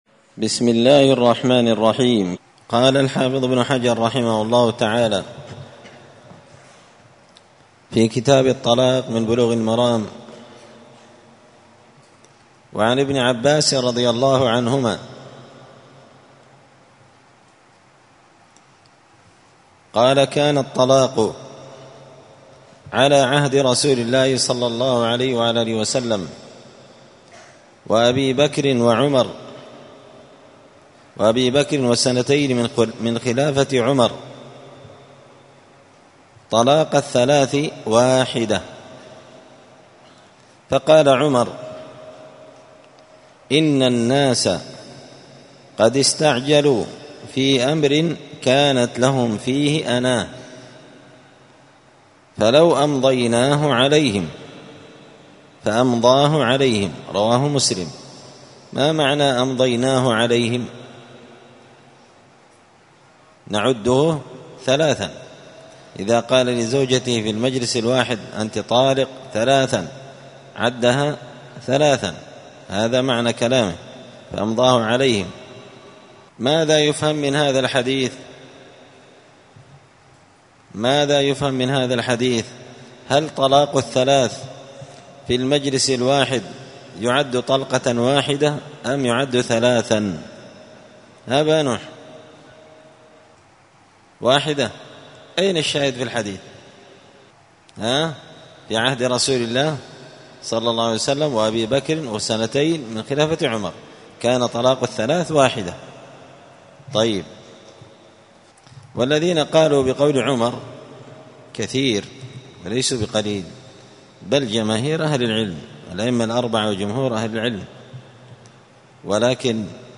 *الدرس الثالث (3) {حكم طلاق الثلاث في المجلس الواحد}*